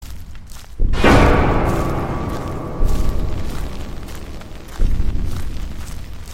slender-man-scare_26255.mp3